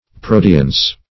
Search Result for " preaudience" : The Collaborative International Dictionary of English v.0.48: Preaudience \Pre*au"di*ence\, n. (Eng.